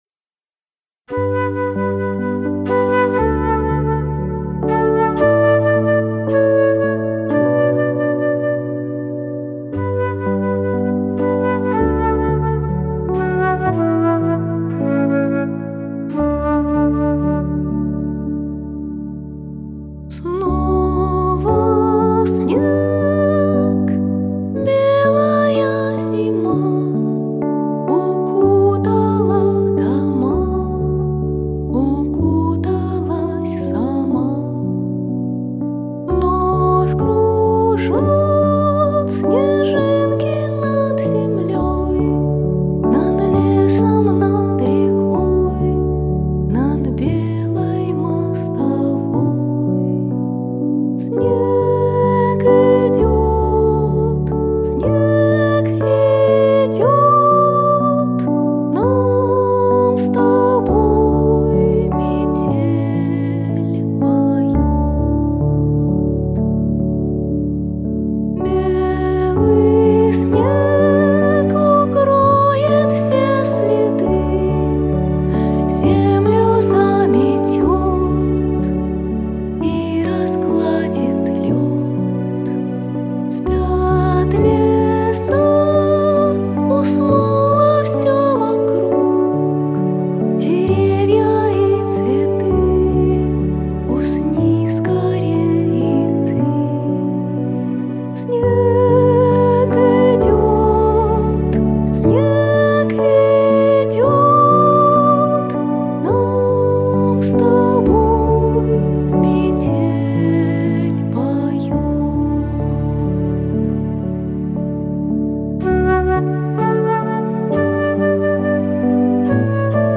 Главная / Песни для детей / Колыбельные песни